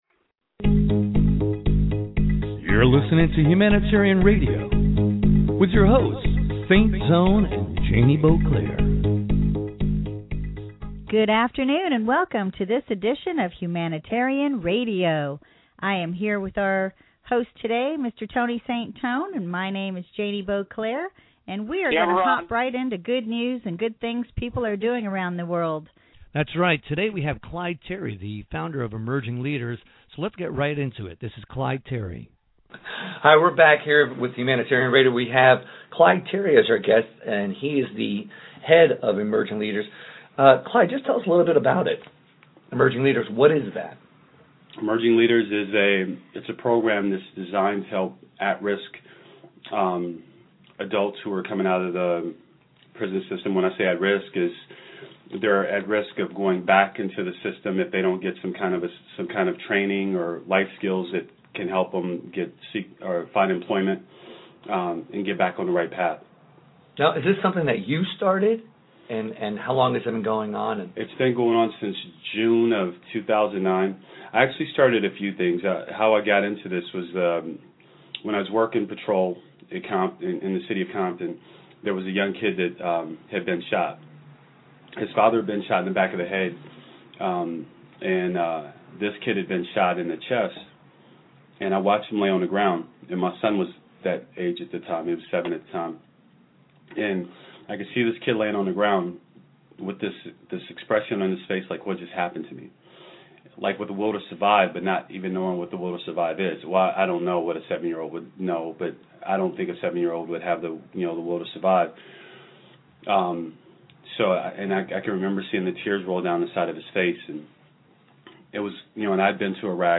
Self Help HR Interview